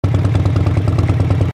From The Cutting Room Floor Jump to navigation Jump to search File File history File usage Metadata EngineLoop.ogg  (Ogg Vorbis sound file, length 1.5 s, 105 kbps) This file is an audio rip from a(n) Adobe Flash game.
EngineLoop.ogg